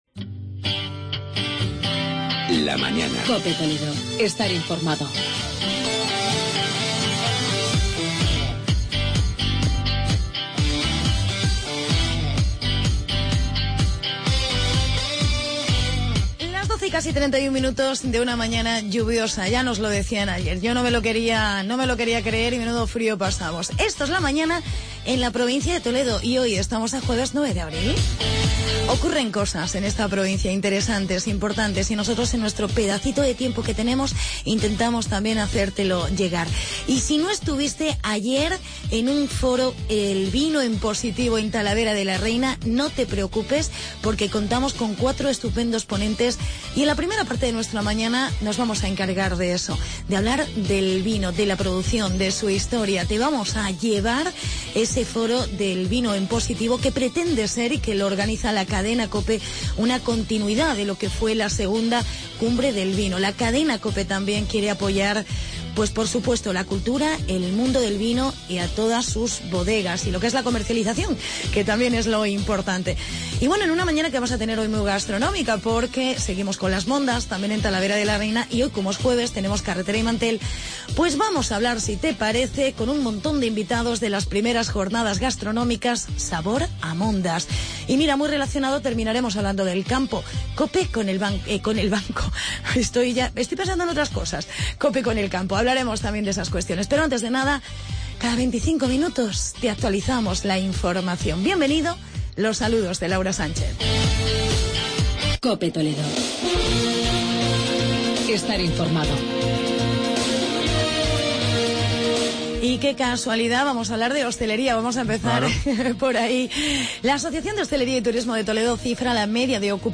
Reportaje "Vino en Positivo" en Talavera.